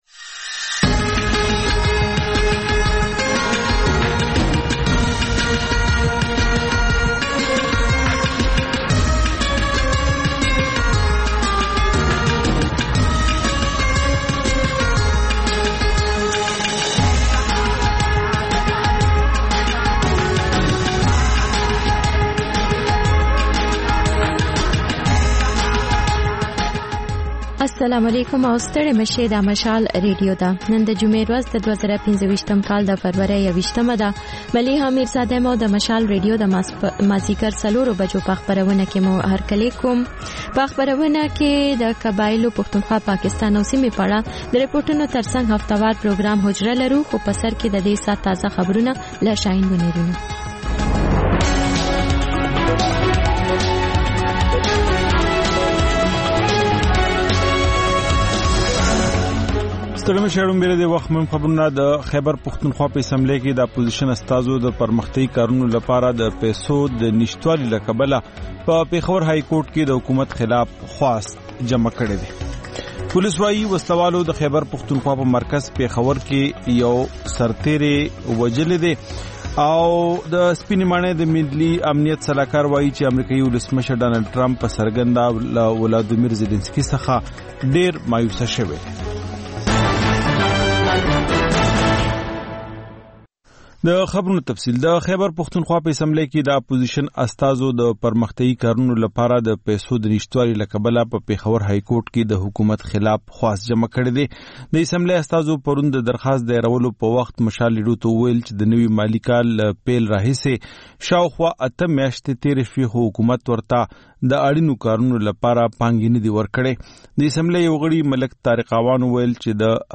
د خپرونې پیل له خبرونو کېږي، ورسره اوونیزه خپرونه/خپرونې هم خپرېږي.